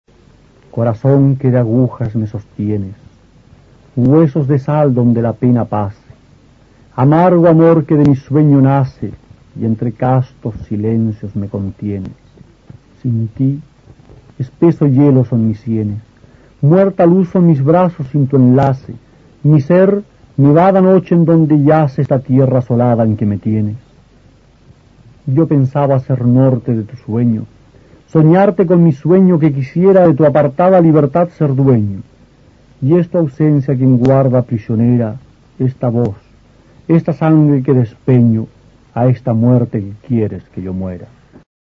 Aquí se puede escuchar al poeta chileno Roque Esteban Scarpa (1914-1995) recitando uno de sus sonetos.
Soneto